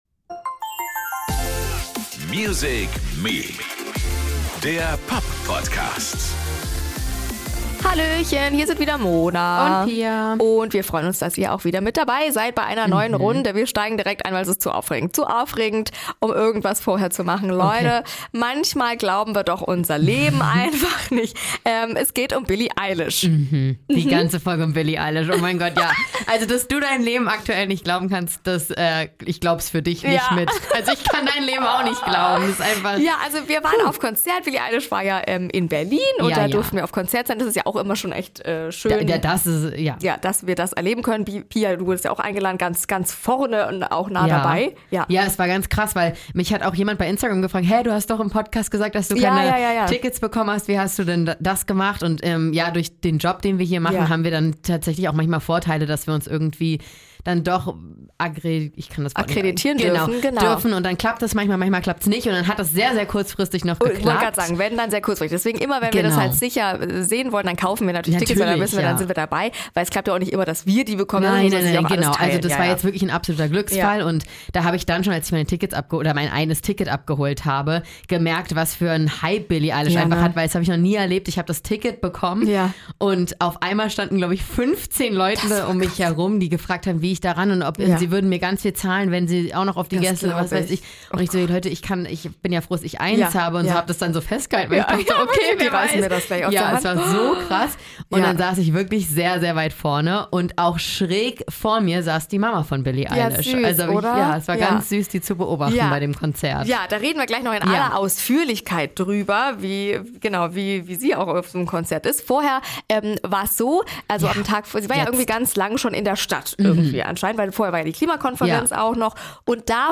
Wir haben Billie Eilish zum exklusiven Interview getroffen und sie hat uns einiges verraten.